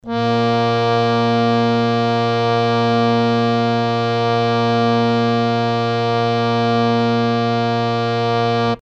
interactive-fretboard / samples / harmonium / As2.mp3